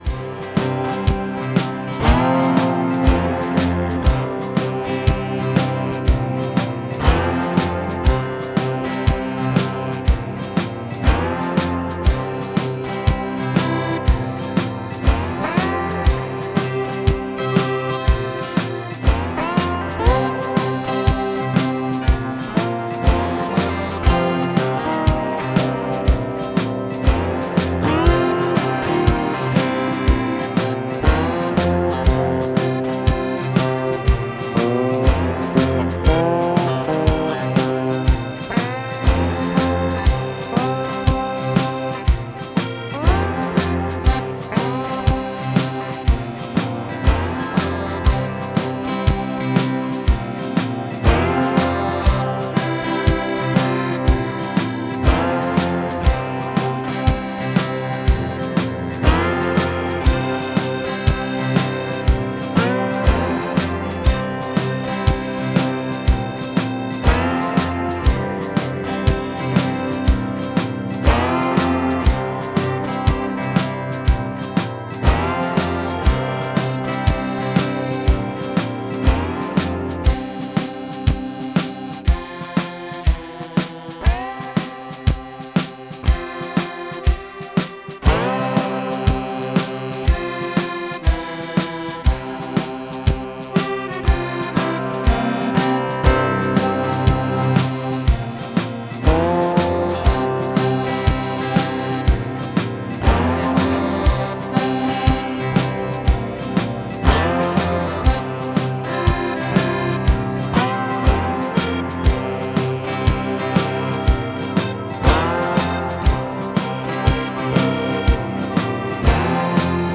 Episch bewegend, Resonator Guitar, Akkordeon.